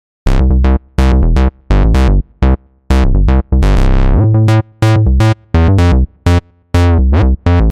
Sequenced Bassでは、LFOモジュレーションによるフィルターの開閉など、より複雑なパターンを生成できます。
▼Sequenced Bassサウンド
LogicPro12_SequencedBass.mp3